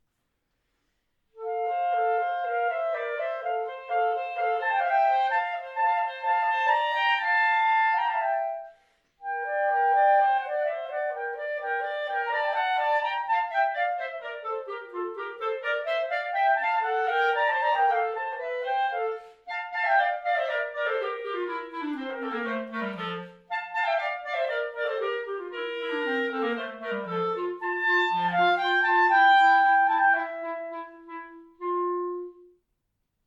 3 Sätze: Allegro non tanto, Menuetto Allegretto, Rondo
Besetzung: 2 Klarinetten